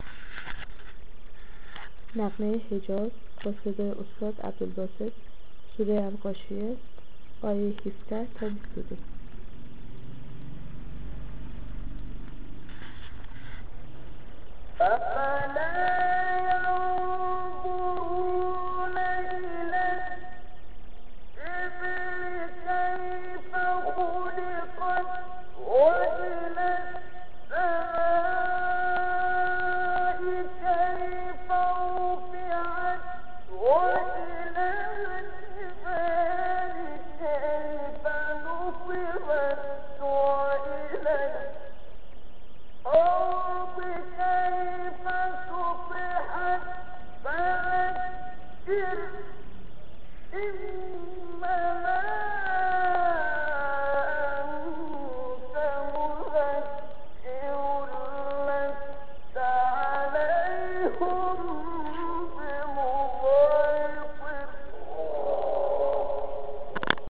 سایت قرآن کلام نورانی - عبدالباسط - حجاز سوره غاشیه آیه 17.mp3
سایت-قرآن-کلام-نورانی-عبدالباسط-حجاز-سوره-غاشیه-آیه-17.mp3